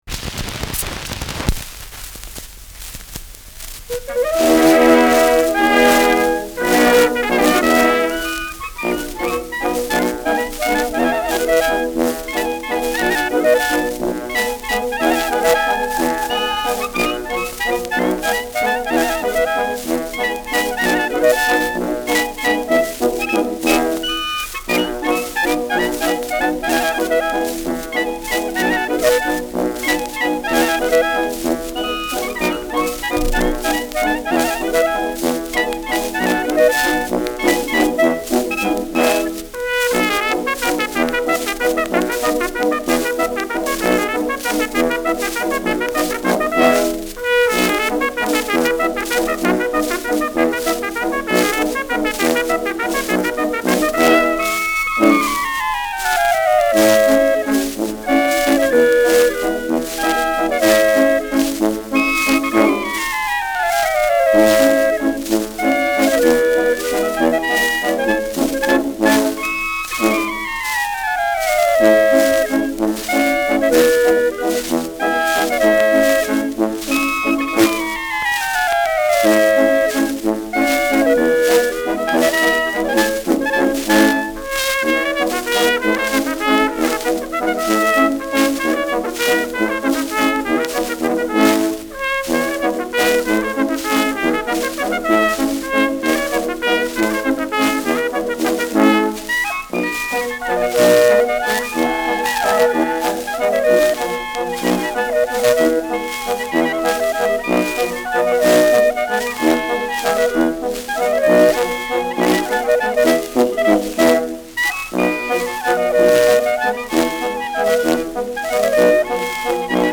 Schellackplatte
Abgespielt : Durchgehendes Nadelgeräusch : Teils leicht verzerrt